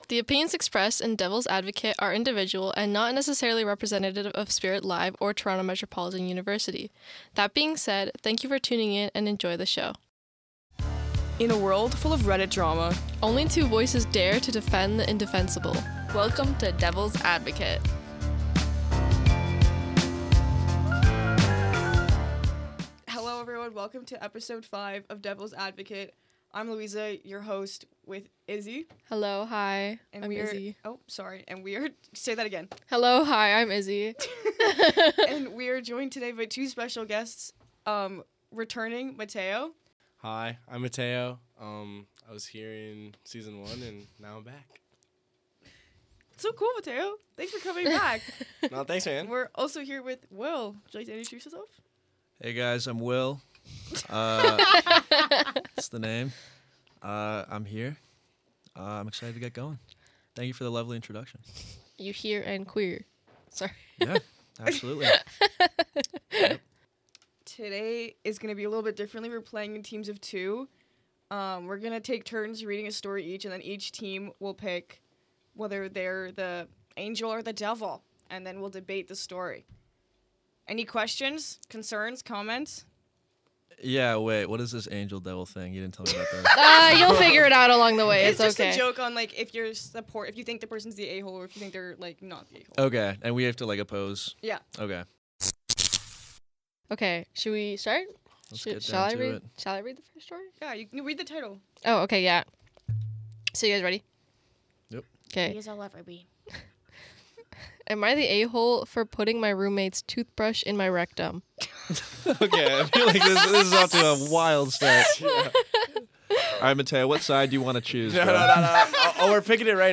Using Reddit “Am I the Asshole?” posts, one host has to defend the person while the other argues against them, no matter their real opinion. It’s part comedy, part debate, and part chaos.